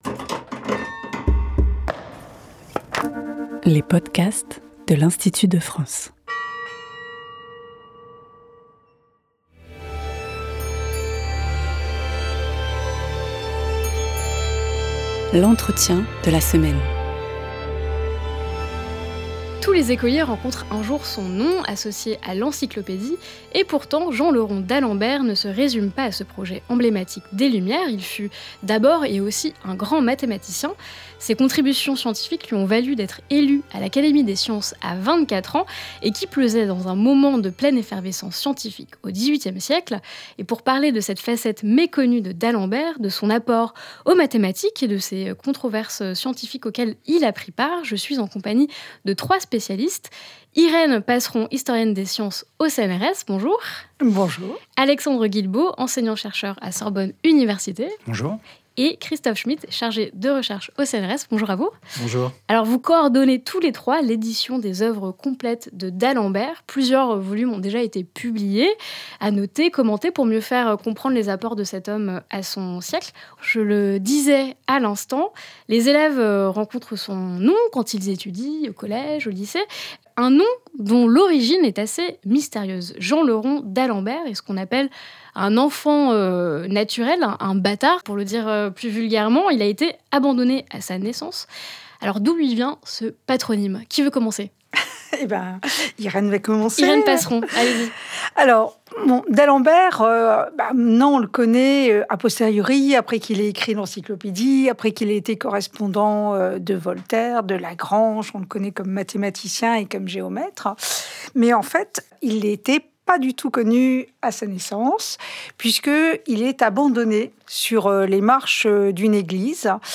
Chaque semaine, nous recevons un académicien ou une académicienne pour une conversation autour d'une actualité qui lui tient à cœur.